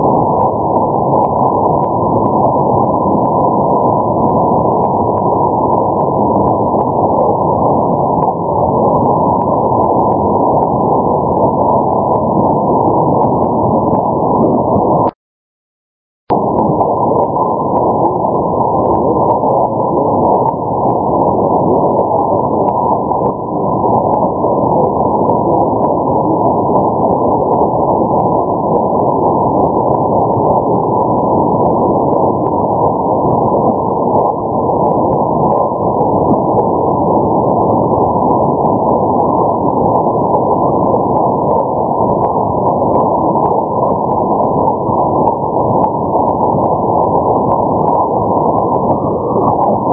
すべて名古屋市内の自宅での音なので人工ノイズ付です。（HI!)
中波海賊局の音
ここで弱く聞こえました。